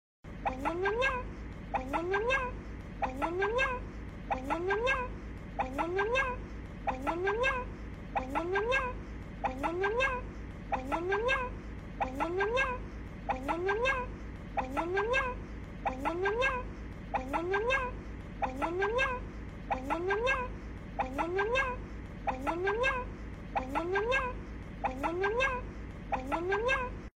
通知音 - Notification Tones